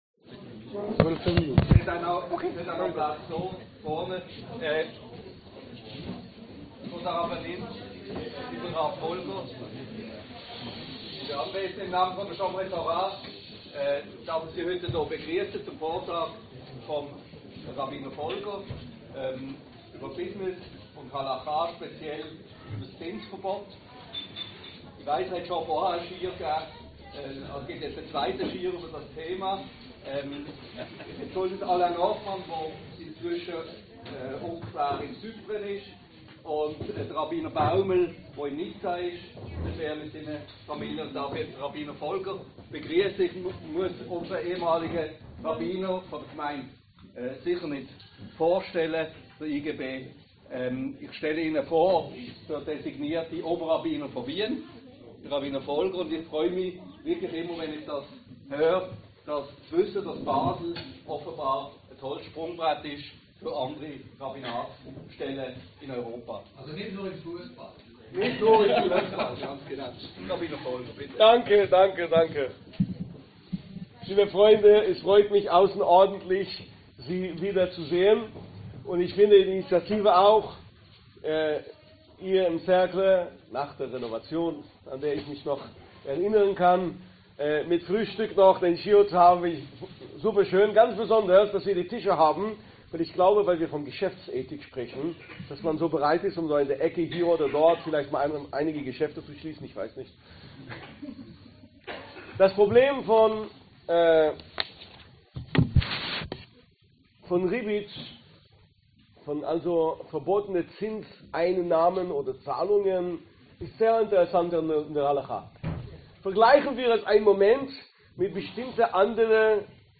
Koscheres Geld – Jüdische Geschäftsethik am Beispiel des biblischen Zinsverbotes (Audio-Schiur) Weshalb verbietet die Tora Zinsen für Kredite?